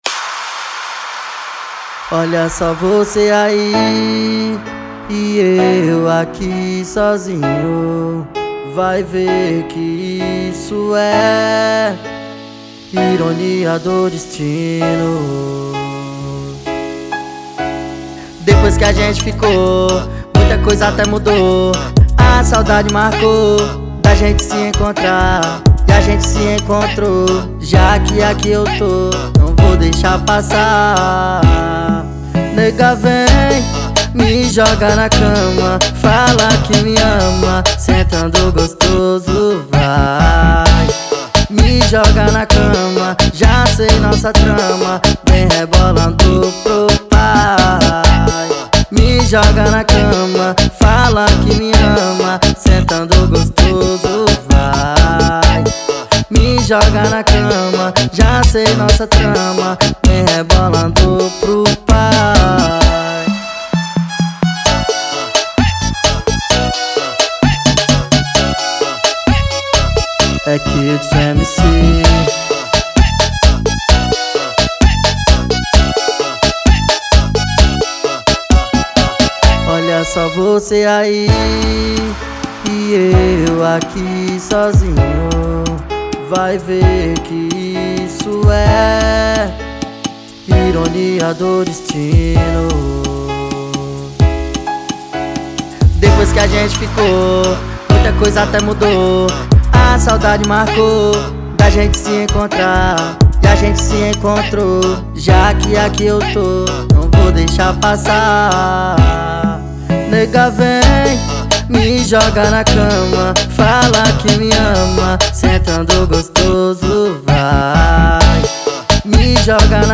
EstiloBregadeira